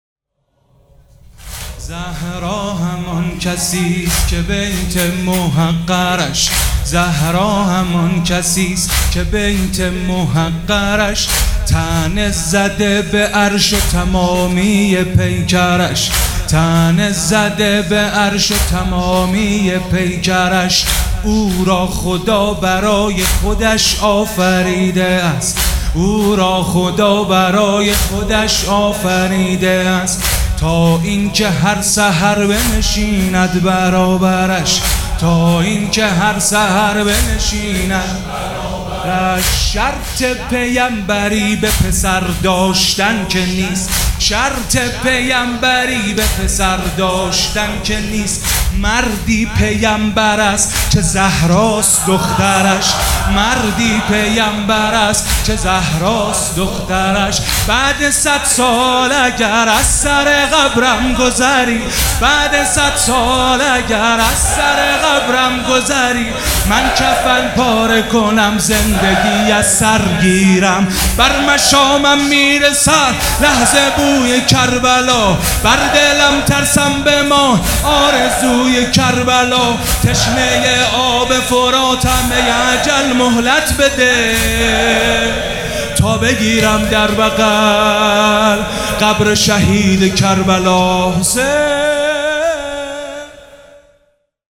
مداح
مراسم عزاداری شب شهادت حضرت زهرا (س)